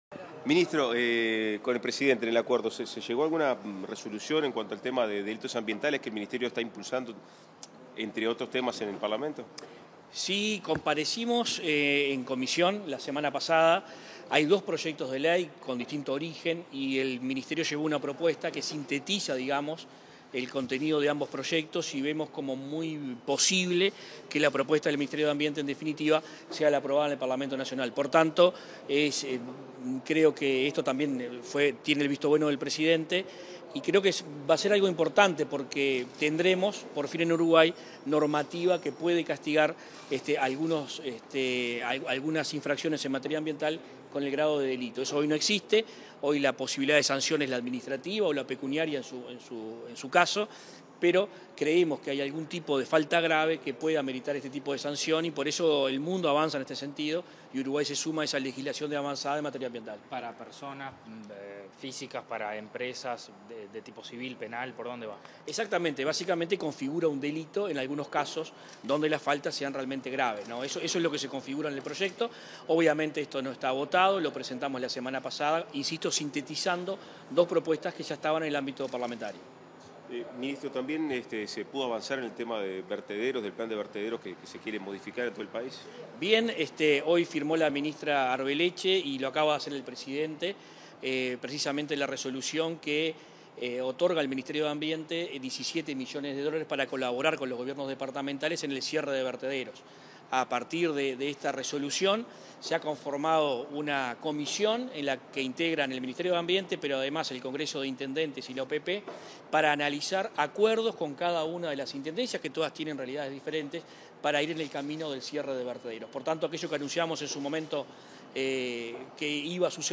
Declaraciones a la prensa del ministro de Ambiente, Adrián Peña
Declaraciones a la prensa del ministro de Ambiente, Adrián Peña 14/11/2022 Compartir Facebook Twitter Copiar enlace WhatsApp LinkedIn Tras participar en la reunión de acuerdos ministeriales con el presidente de la República, Luis Lacalle Pou, este 14 de noviembre, el ministro de Ambiente, Adrián Peña, realizó declaraciones a la prensa.